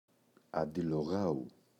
αντιλογάου [andilo’γau]